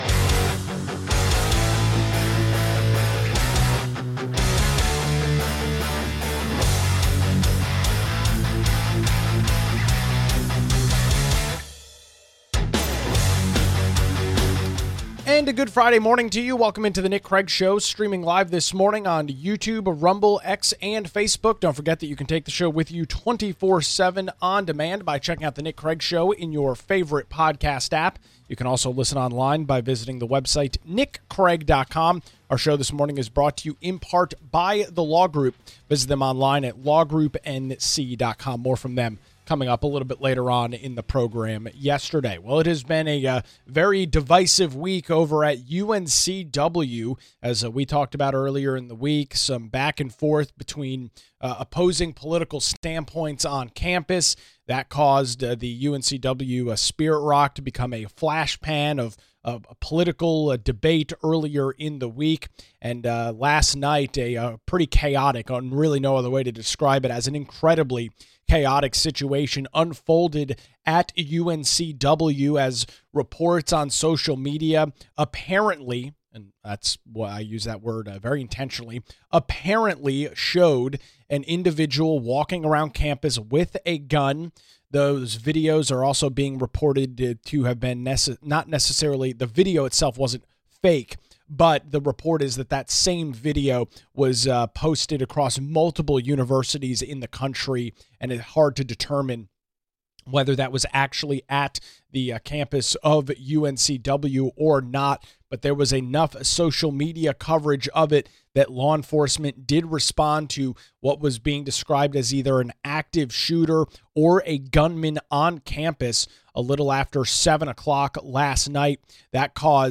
UNCW cancels Friday classes after reports of gunman on campus determined to be false alarm, plus State Auditor Dave Boliek joins the show to discuss an audit of the Battleship. This show aired on Friday, September 19th, 2025.